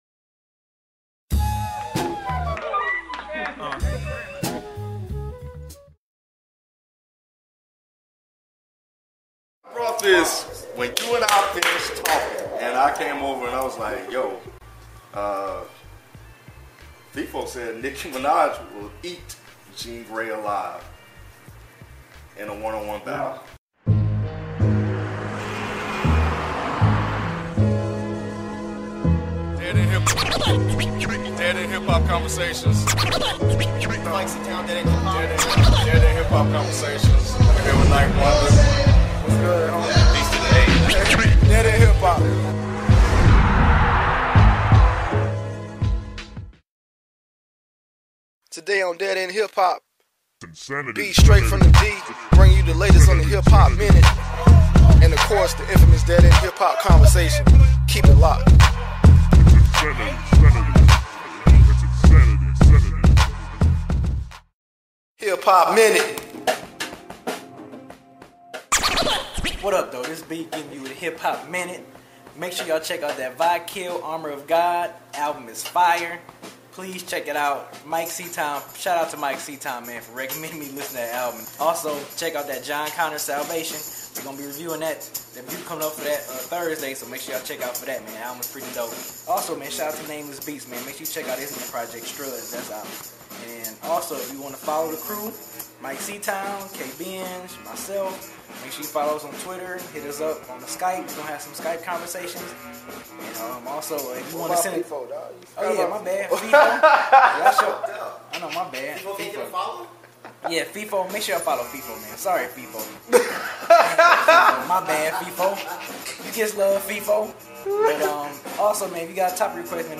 Webisode 25: Nicki Minaj vs. Jean Grae? (Skype Guest Debut) | Dead End Hip Hop